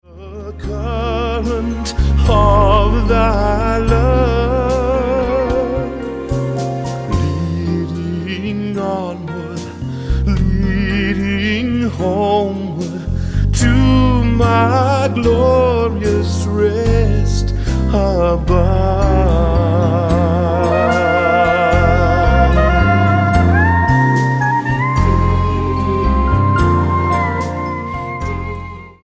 STYLE: MOR / Soft Pop
operatic bass baritone swells over the gospel underlay
He can funk it up too.